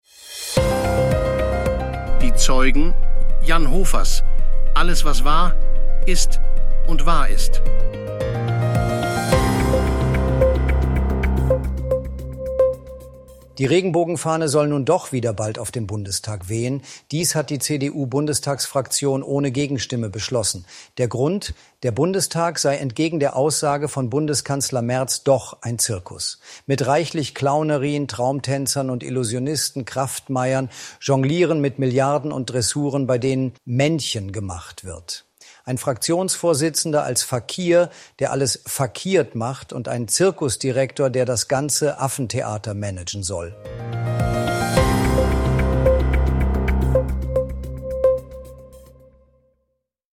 (100% KI-ssel)